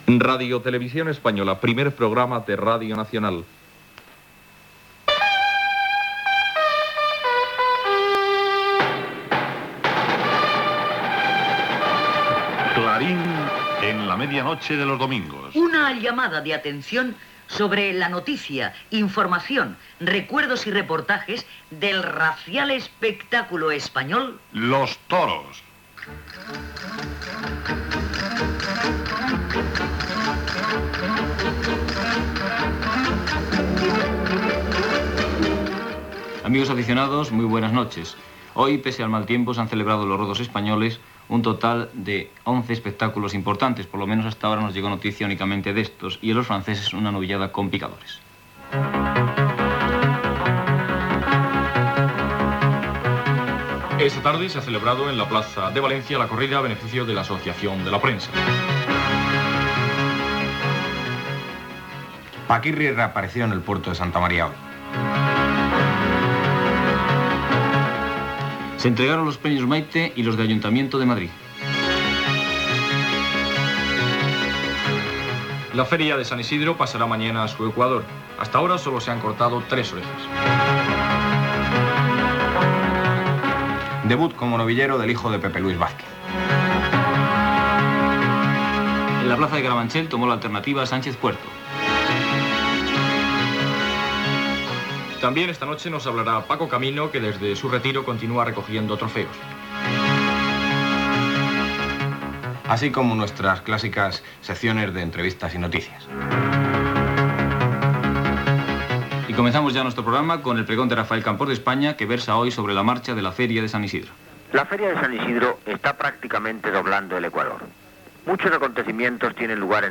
Identificació de l'emissora, com RTVE Primer Programa de Radio Nacional, careta del programa, sumari informatiu, comentari sobre la fira taurina de San Isidro
Informatiu